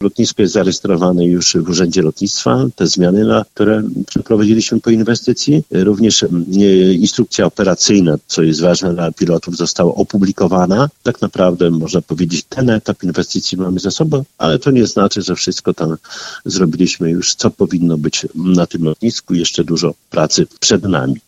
Jak dodaje prezydent, lotnisko jest już zarejestrowane w Urzędzie Lotnictwa.